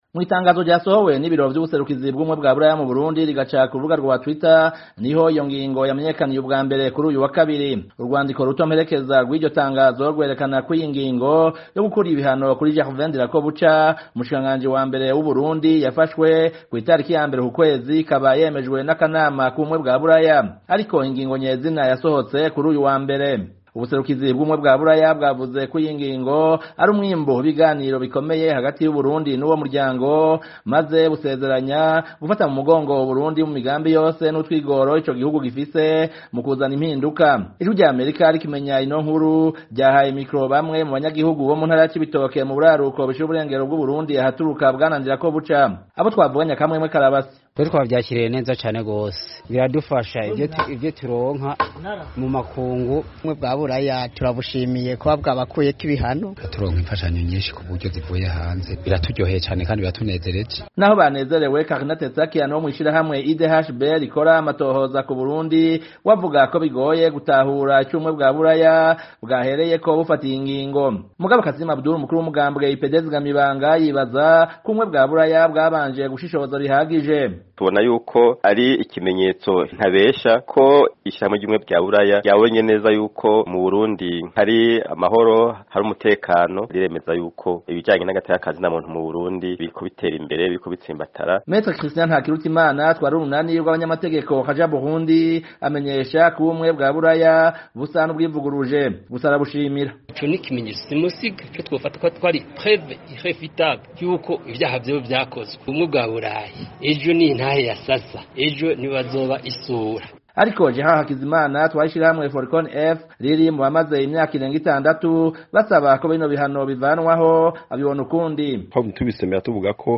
Uku ni ko yabidusiguriye kuri terefone.